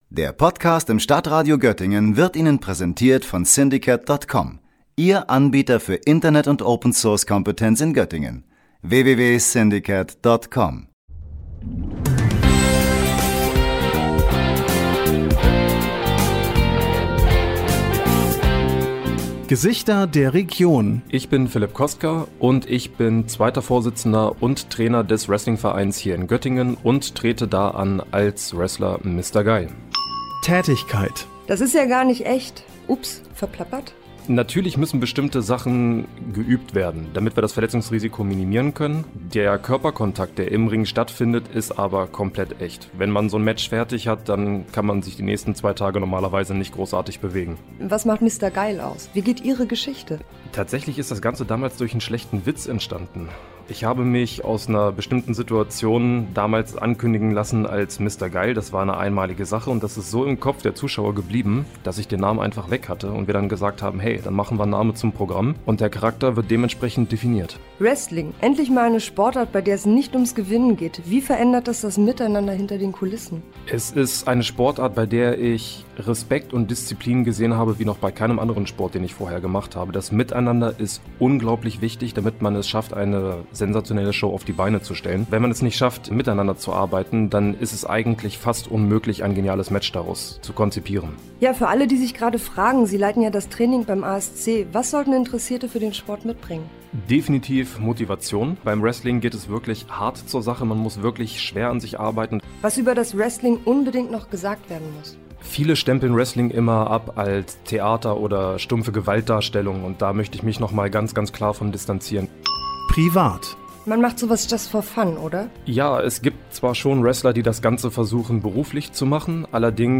Urheber: music by Michael Bertram, mb recording